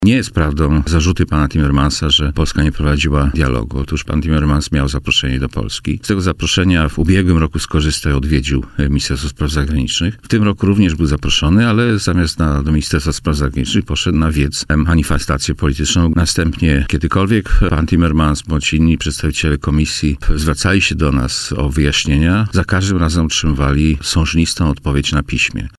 Minister Spraw Zagranicznych – Witold Waszczykowski przypomniał w poranku „Siódma9” na antenie Radia Warszawa, że Polska zawsze była otwarta na współpracę i wyjaśnienia przed komisją.